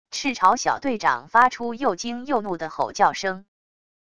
赤潮小队长发出又惊又怒地吼叫声wav音频